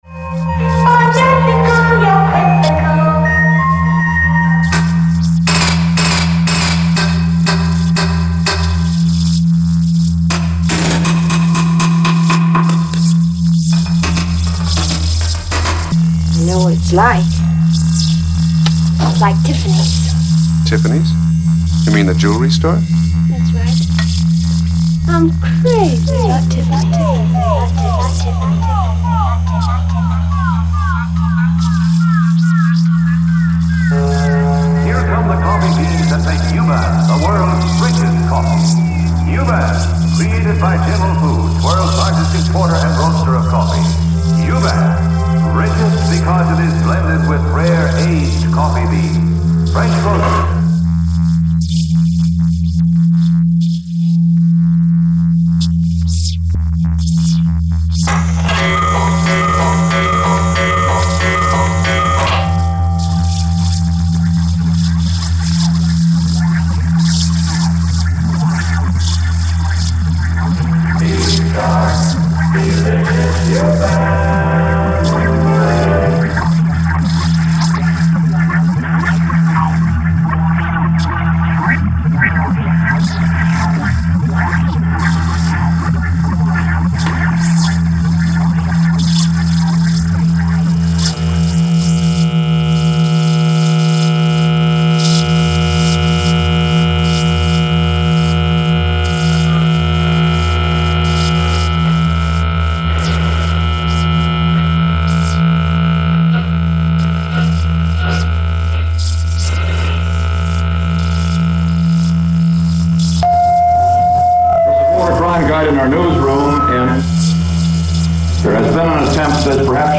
Soundscape